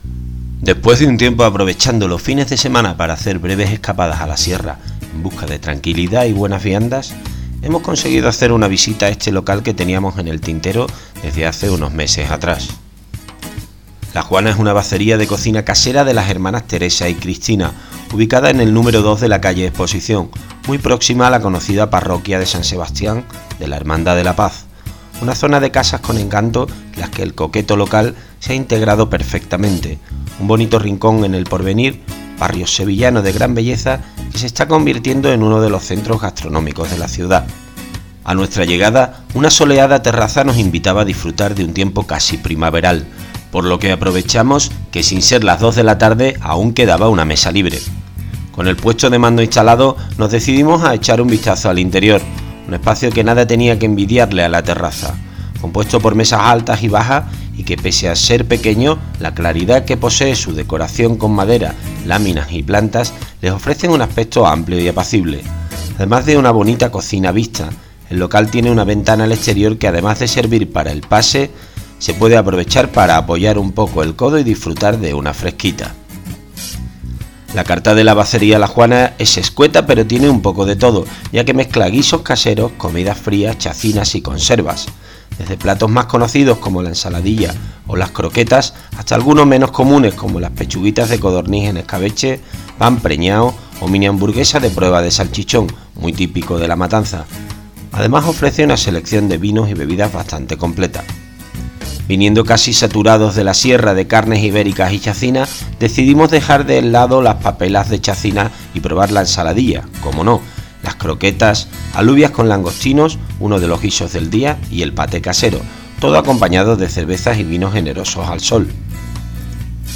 Aquí tenéis el post audiodescrito: